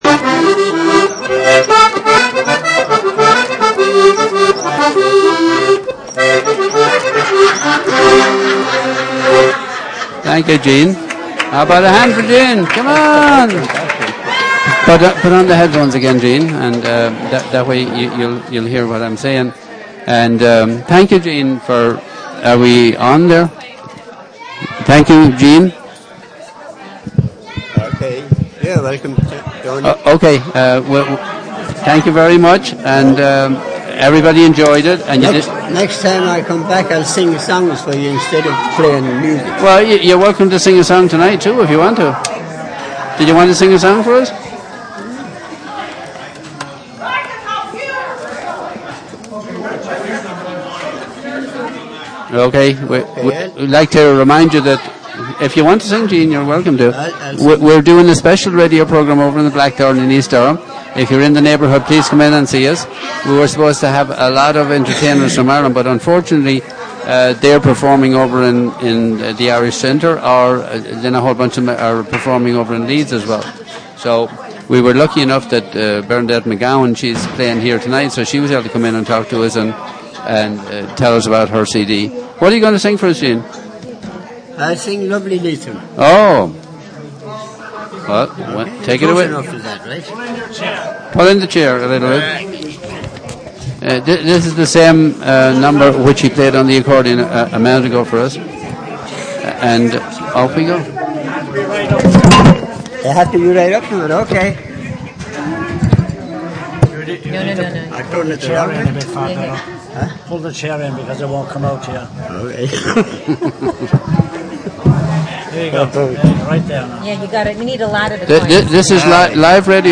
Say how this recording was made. Irish Show live from Blackthorne Resort (second hour). (Audio) Irish Arts Week: Live from Blackthorne Resort: Jul 13, 2011: 7pm - 9pm